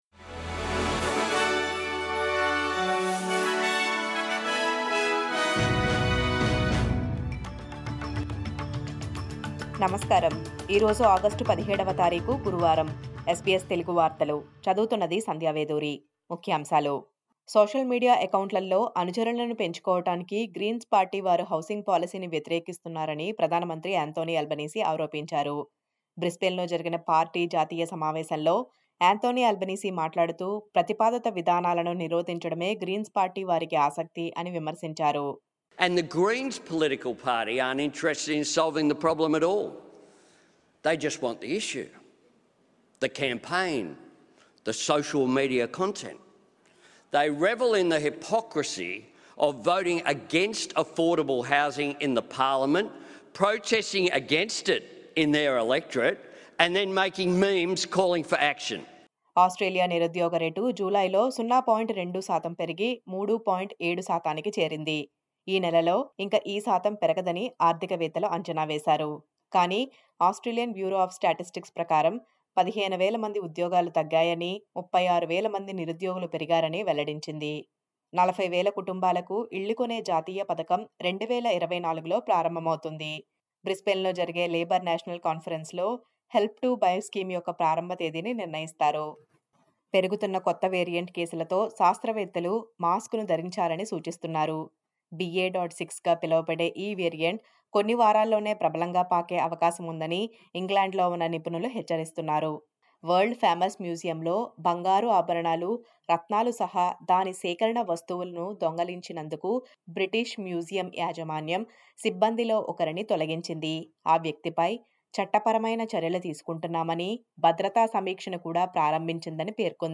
SBS Telugu వార్తలు.